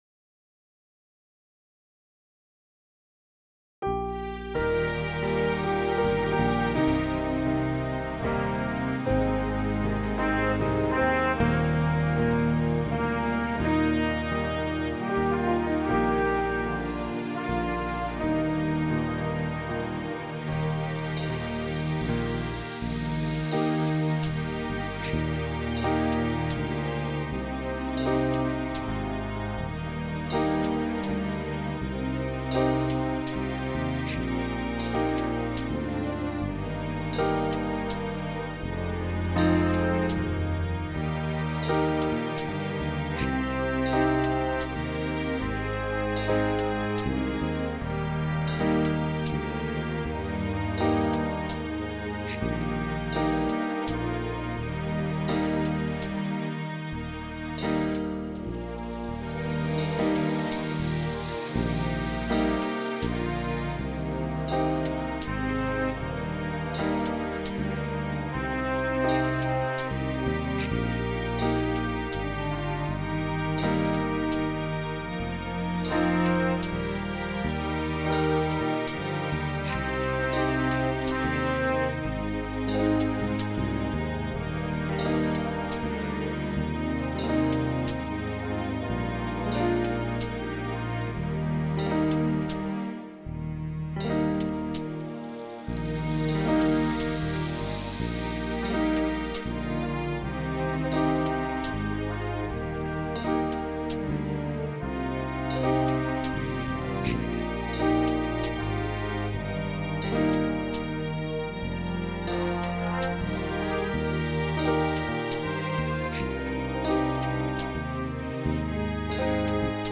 Accompaniment (Copyright)